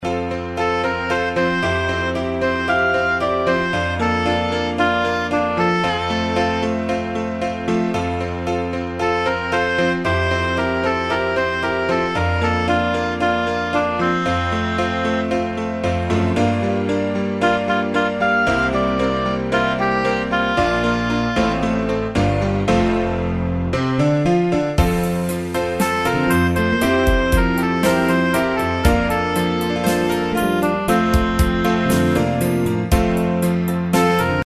Tempo: 114 BPM.
MP3 with melody DEMO 30s (0.5 MB)zdarma